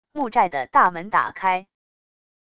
木寨的大门打开wav下载